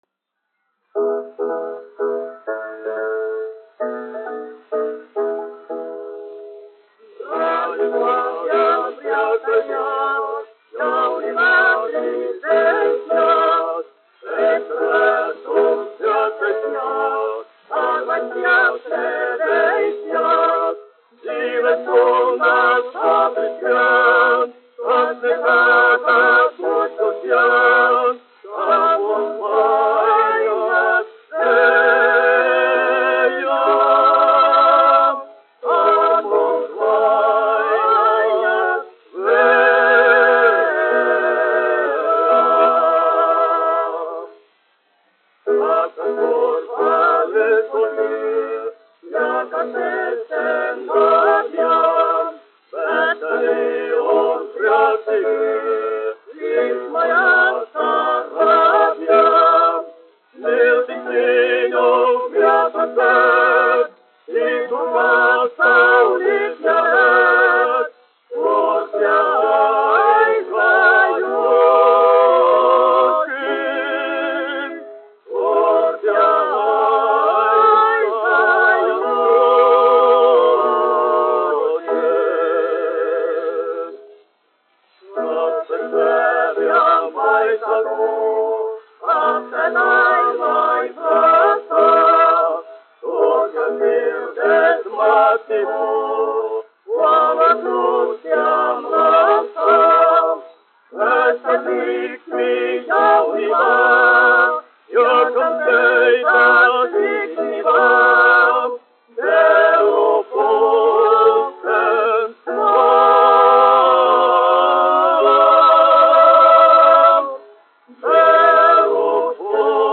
1 skpl. : analogs, 78 apgr/min, mono ; 25 cm
Vokālie kvarteti ar klavierēm
Izpilda Jaunā Rīgas teātra vīru kvartets
Latvijas vēsturiskie šellaka skaņuplašu ieraksti (Kolekcija)